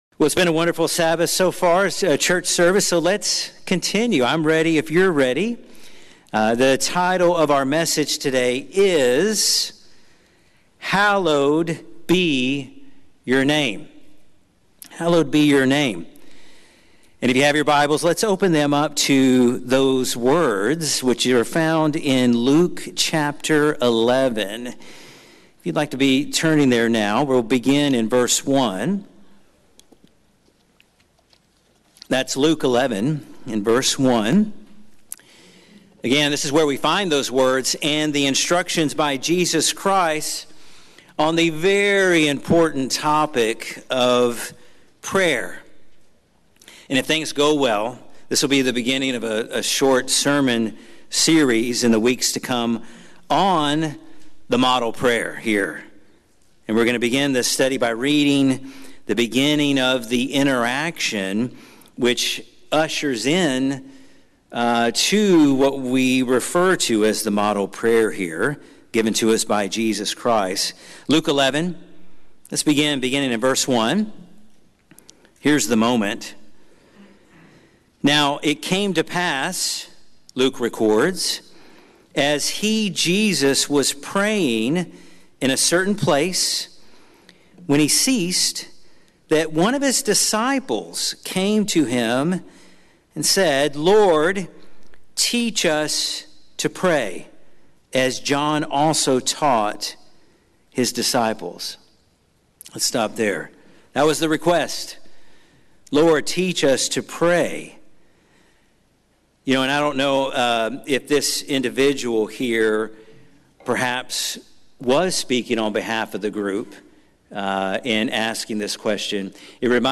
This sermon is the start of a series of sermons on the Lord's prayer, the prayer outline Jesus gave when his disciples asked him to teach them how to pray.